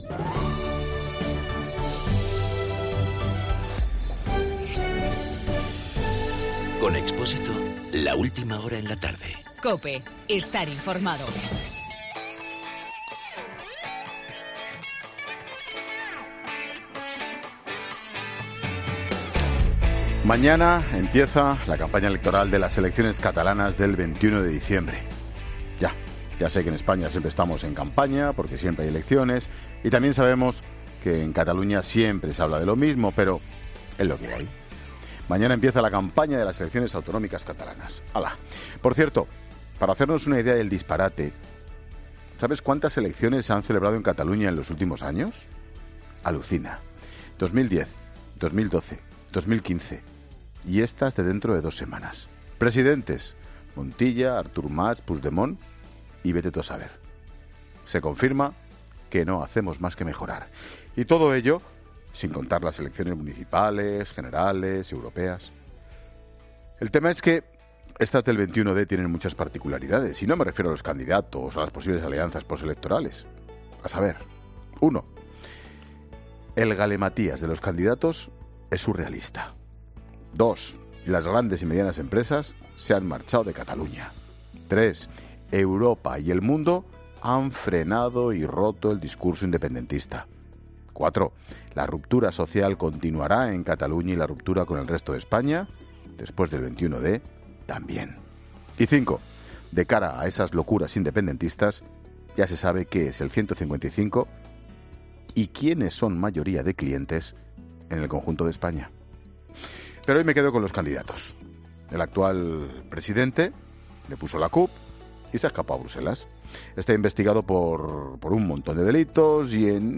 AUDIO: El comentario de Ángel Expósito antes de que comience la campaña electoral catalana.
Monólogo de Expósito